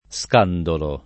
Sk#ndolo]: per ovviare a’ pericolosi scandoli dell’età presente [